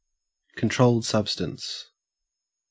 Ääntäminen
Ääntäminen AU